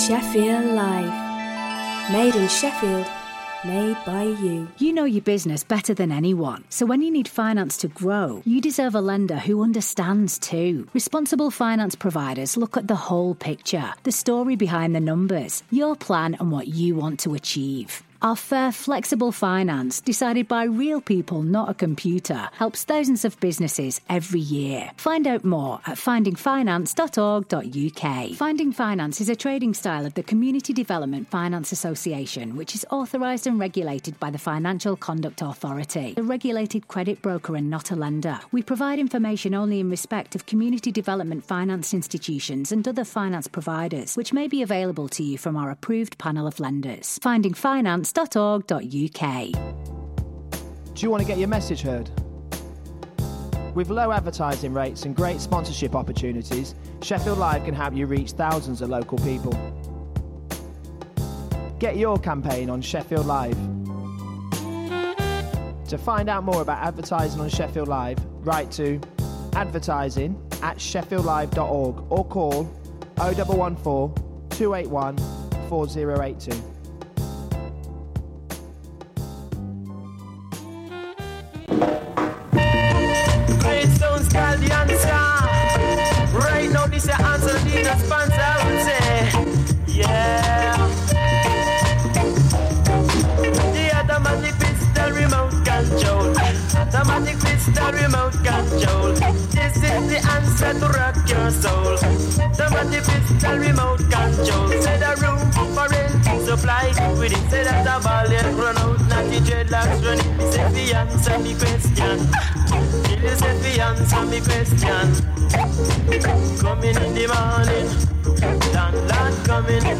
Business news, debate and interviews for anyone interested in growing or starting a business.